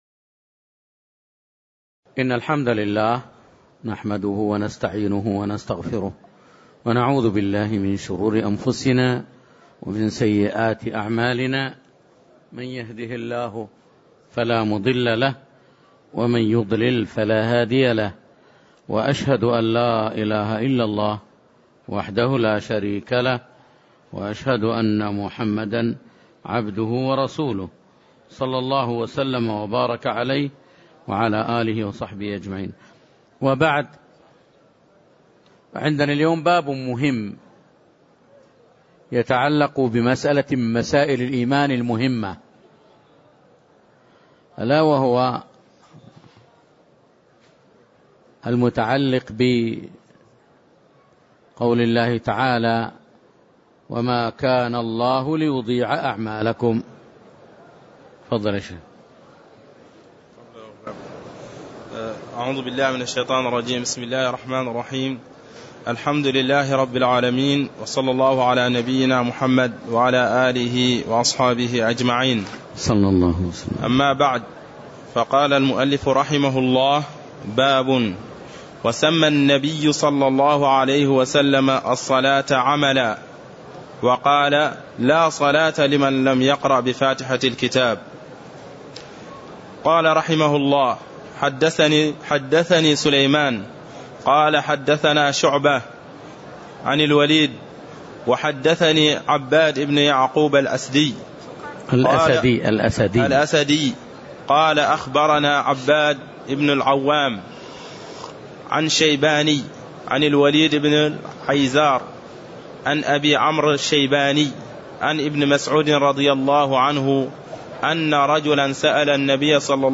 تاريخ النشر ١٦ ربيع الثاني ١٤٣٦ هـ المكان: المسجد النبوي الشيخ